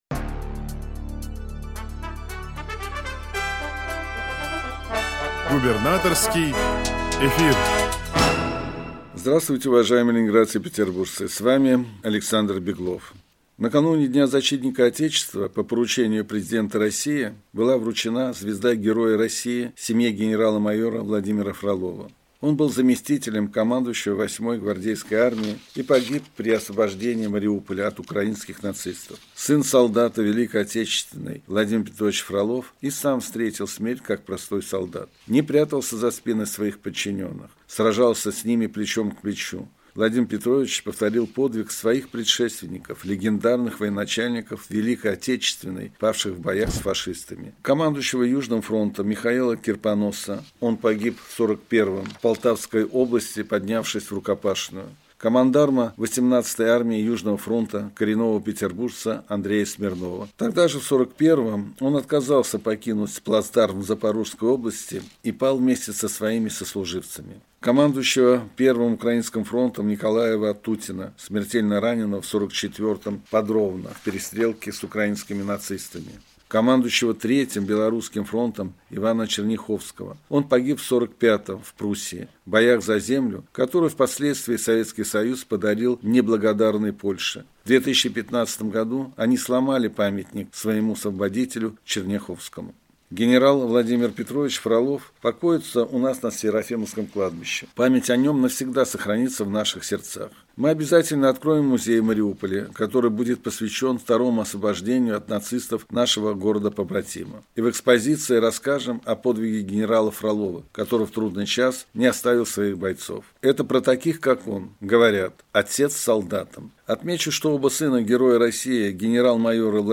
Радиообращение – 26 февраля 2024 года